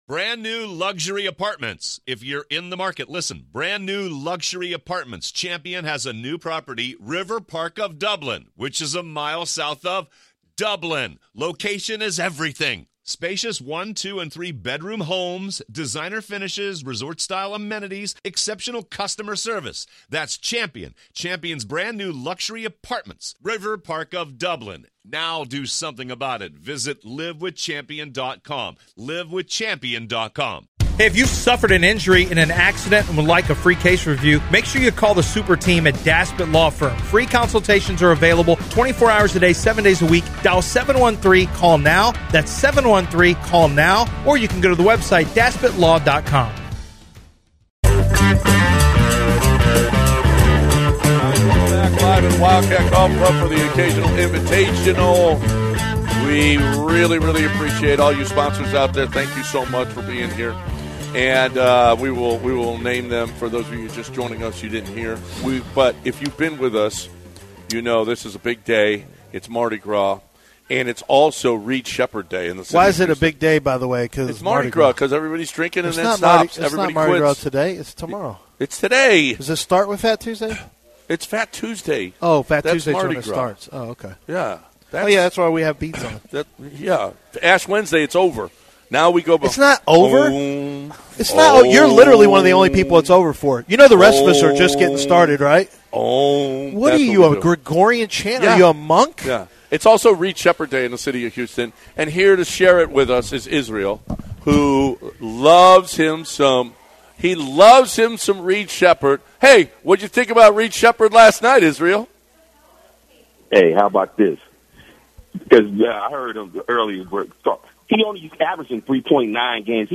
3-4 Hour 2: Live at Wildcat Golf Club for the Occasional Invitational: Rockets' Potential and How It Impacts the Rest of the Season, Conference Tournaments, and Championship Contention